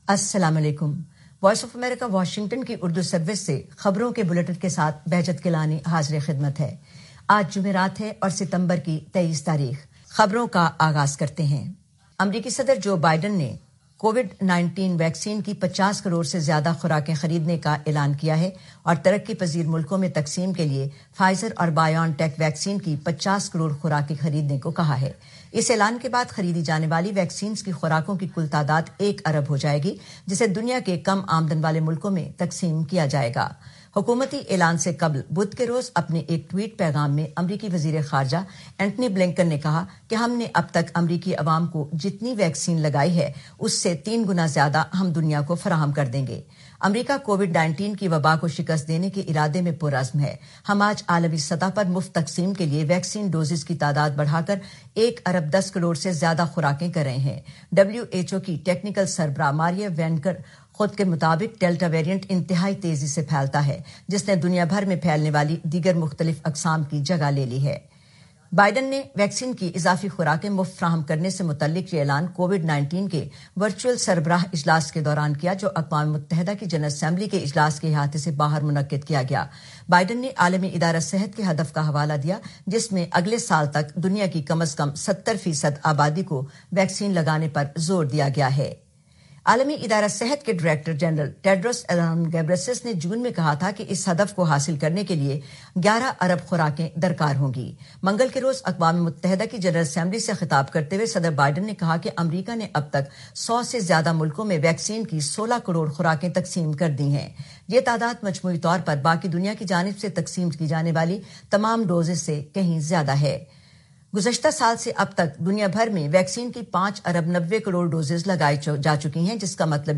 نیوز بلیٹن 2021-23-09